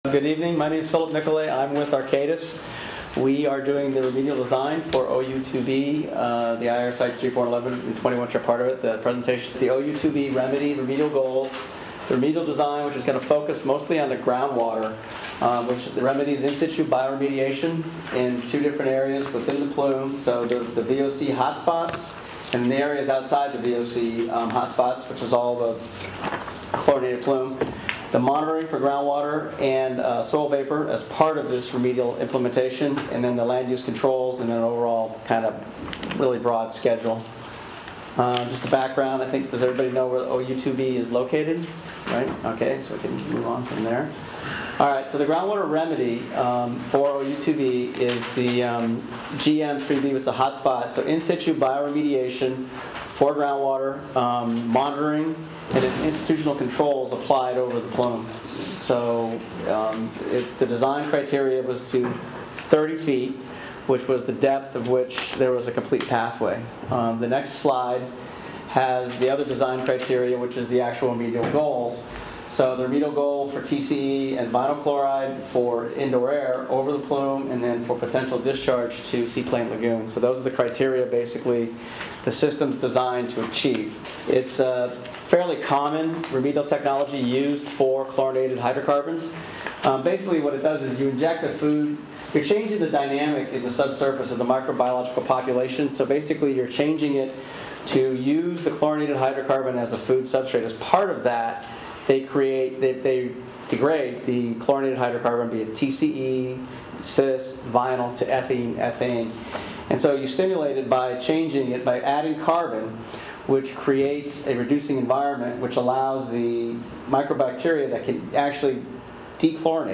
rab-meeting-ou-2b-presentation.m4a